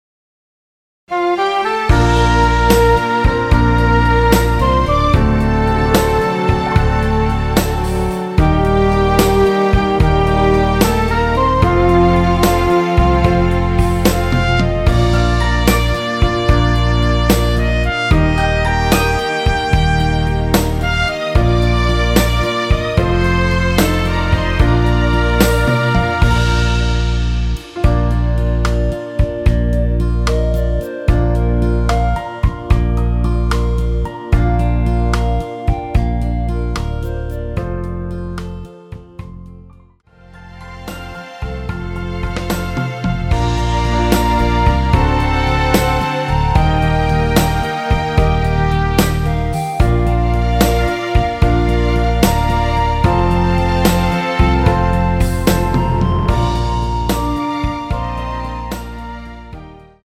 원키에서(+4)올린 멜로디 포함된 MR입니다.(미리듣기 확인)
Bb
앞부분30초, 뒷부분30초씩 편집해서 올려 드리고 있습니다.
중간에 음이 끈어지고 다시 나오는 이유는